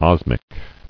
[os·mic]